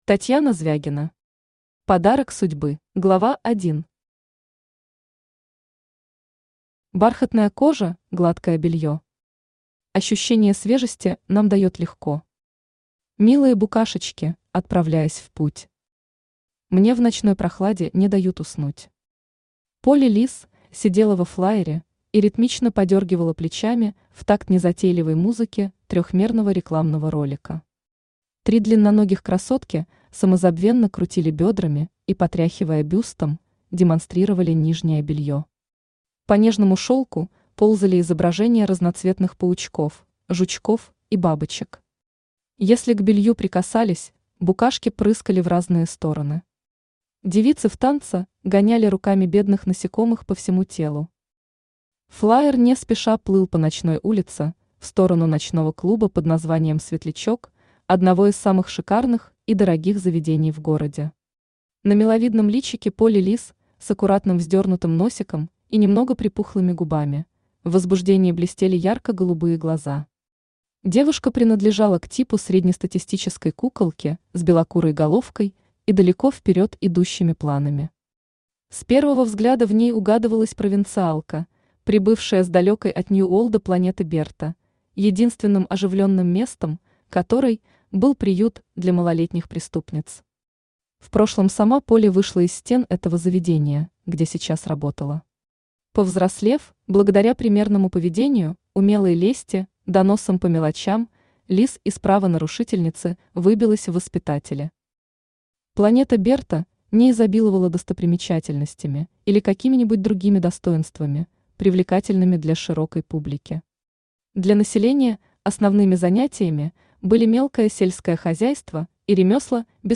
Аудиокнига Подарок судьбы | Библиотека аудиокниг
Aудиокнига Подарок судьбы Автор Татьяна Михайловна Звягина Читает аудиокнигу Авточтец ЛитРес.